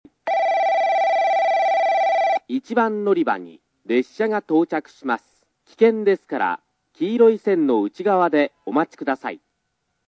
スピーカー：ソノコラム小
接近放送（男性）　(49KB/10秒)